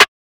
Snare (Troublemaker).wav